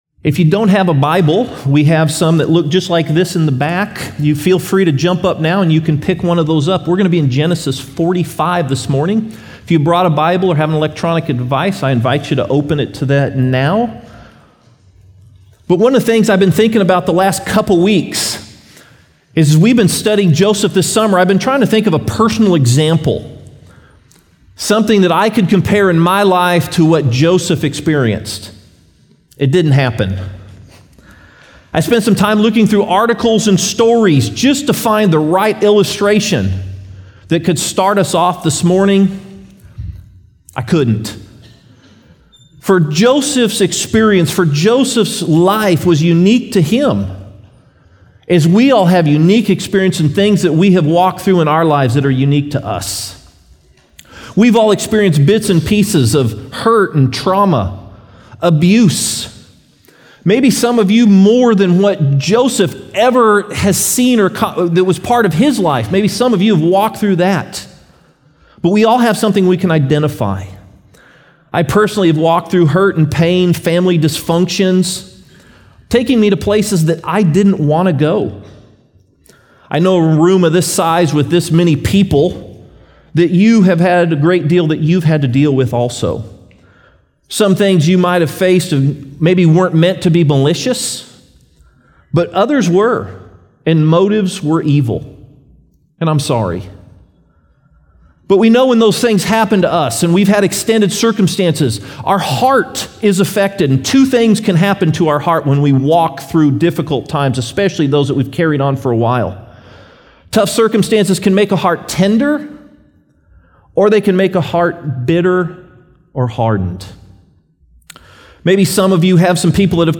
A Sunday morning series about God’s faithfulness to Joseph, and ultimately His people Israel, through all of the twists and turns of his story.